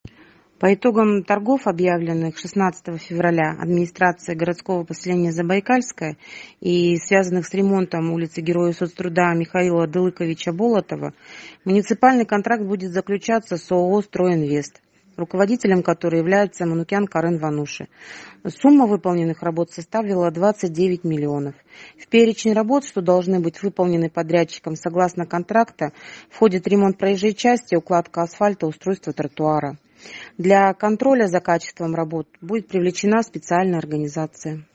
Кто стал подрядчиком рассказывает заместитель главы администрации ГП “Забайкальское”.